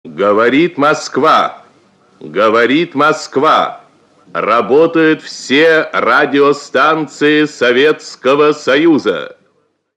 Звуки советского радио: В эфире все станции Советского Союза